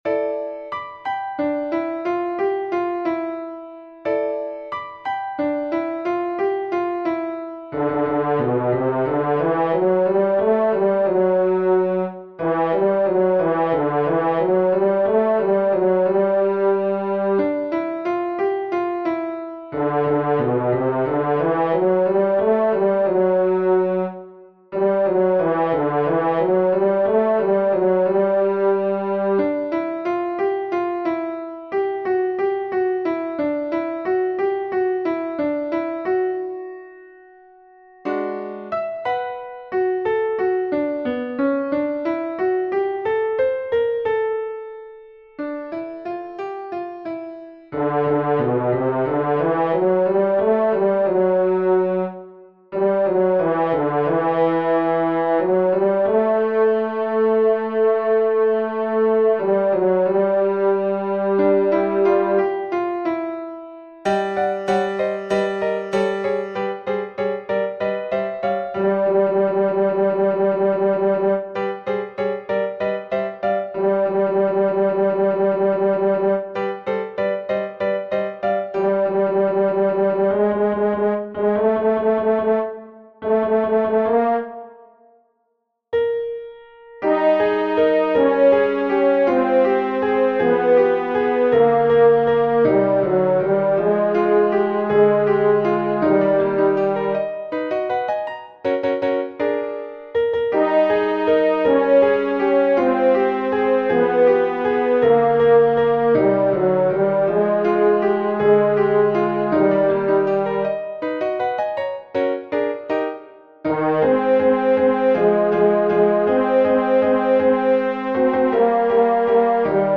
Voice part practice (up to Letter I, page 11):
The featured voice is a horn.
TENOR 1